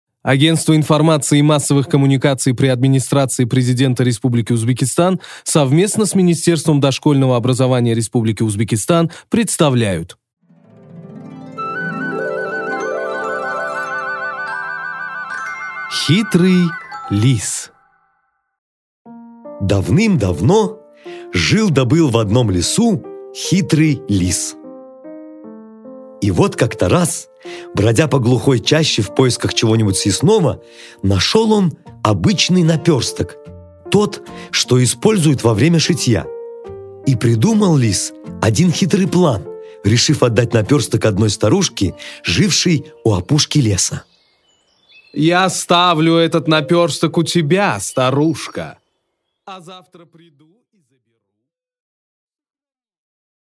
Аудиокнига Хитрый лис